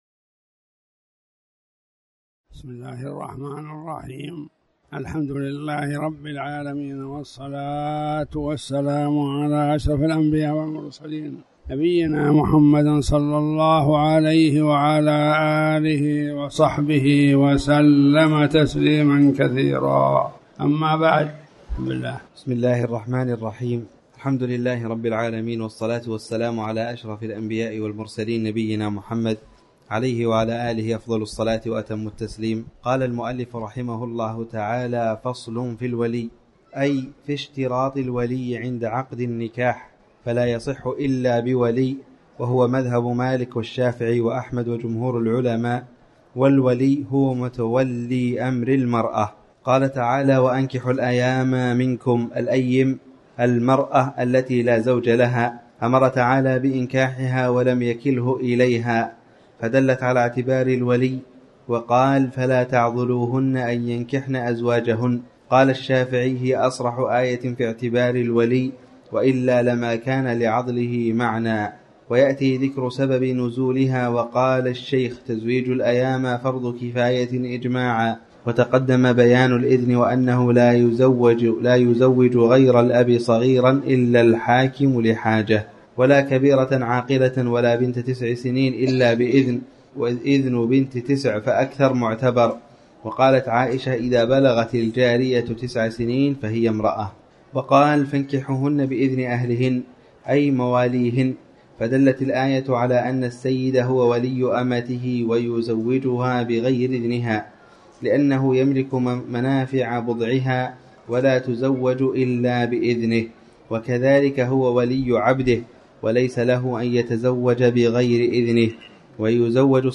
المكان: المسجد الحرام